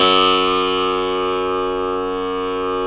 53s-pno03-F0.aif